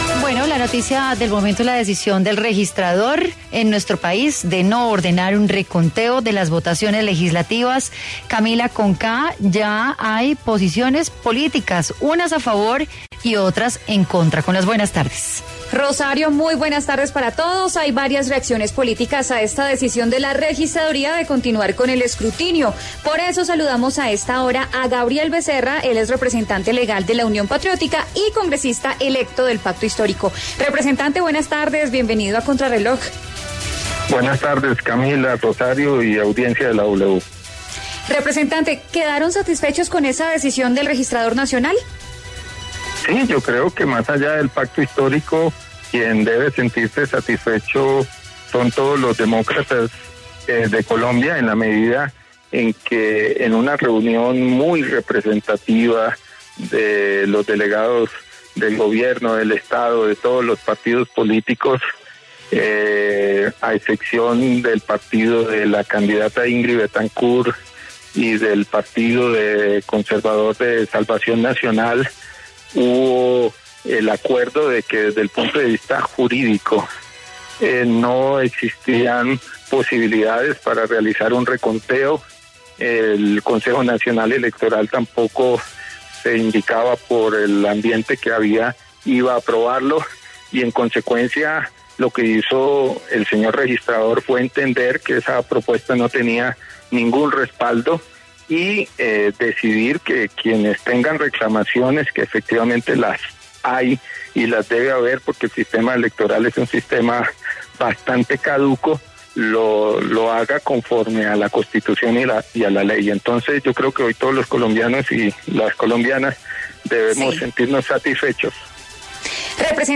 En diálogo con Contrarreloj, el representante electo a la Cámara por el Pacto Histórico, Gabriel Becerra habló sobre la decisión de la Registraduría de no presentar solicitud formal para que se haga el reconteo de votos.